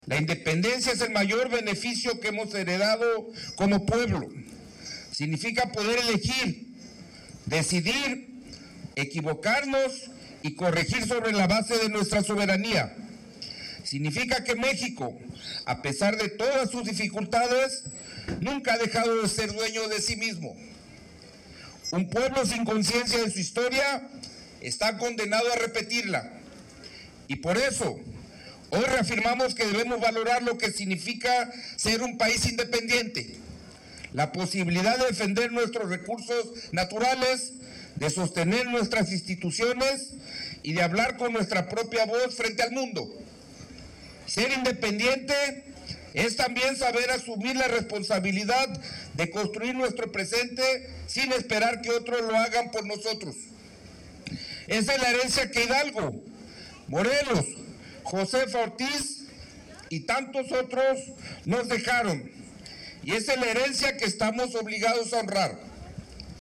secretario general Alberto Rentería Santana
Al término del desfile, en la explanada del palacio municipal se llevó a cabo la ceremonia cívica, donde el secretario general reconoció la participación y compromiso de cada contingente.
INSERT-.DESFILE-CIVICO-MILITAR-SJCmp3.mp3